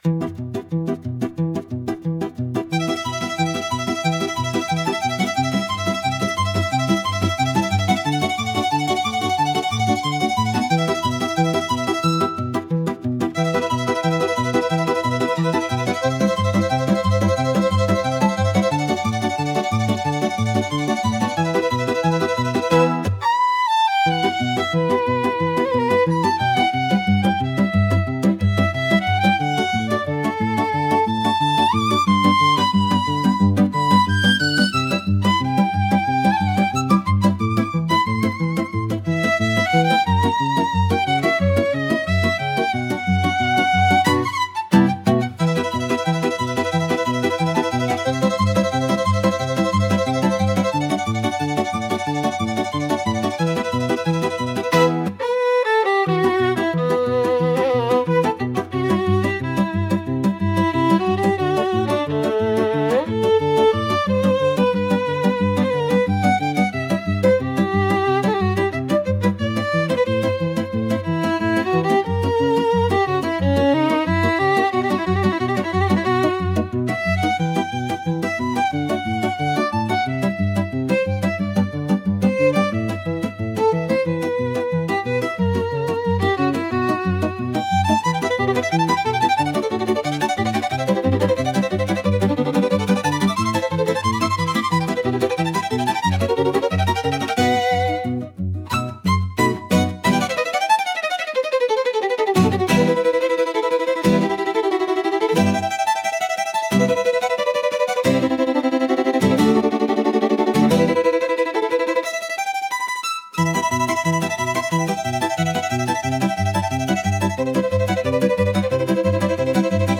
情熱が弾けるような、超高速バイオリンが駆け抜けるクラシック曲。
華やかな旋律、めまぐるしく切れ込むフレーズ、 そして緊迫感のあるリズムが重なり、スピード感を生み出しています。